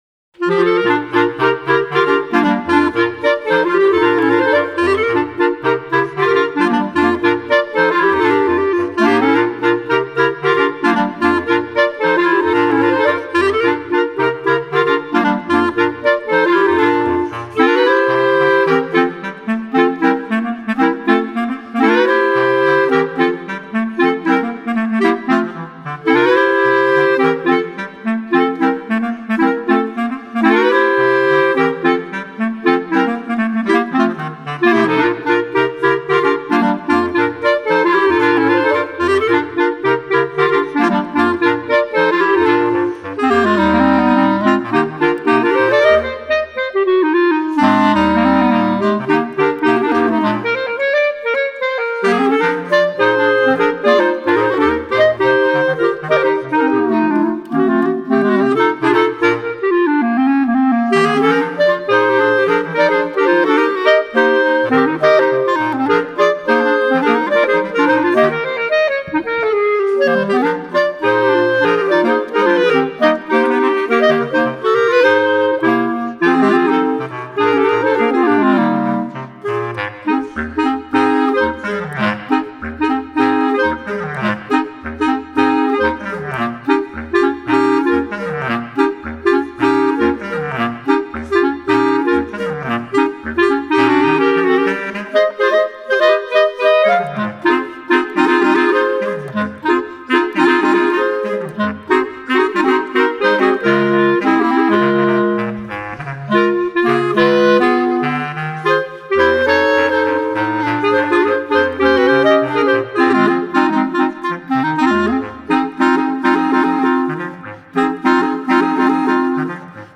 Dechové kvarteto
typ: studiová nahrávka, najednou